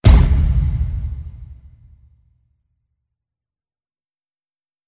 Heavy Stomp.wav